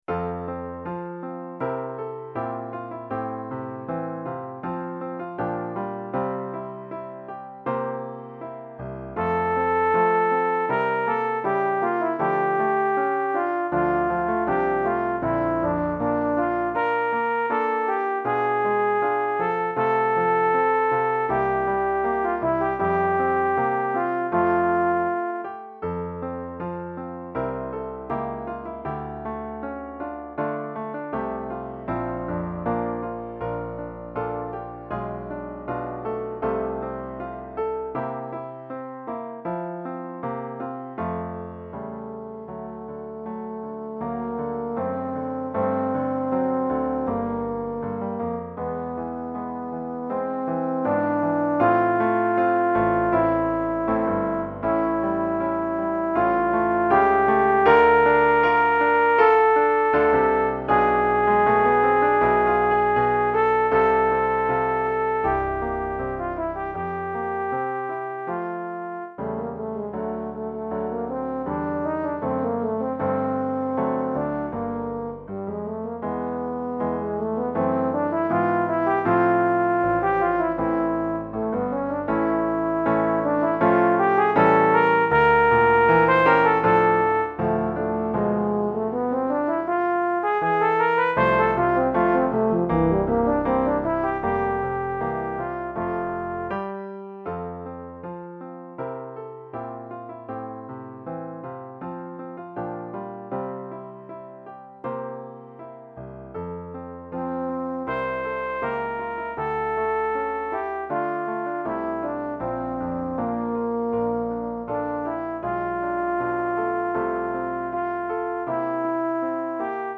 Saxhorn et Piano